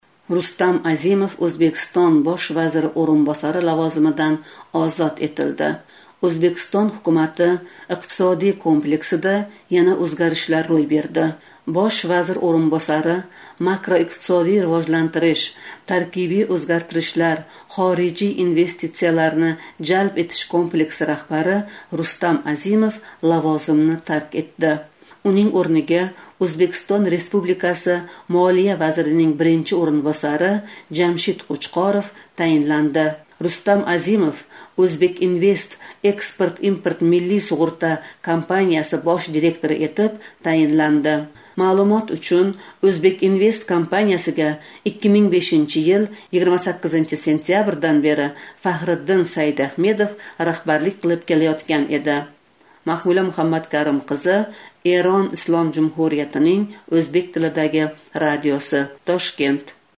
Бу ҳақда Тошкент шаҳридан ҳамкоримиз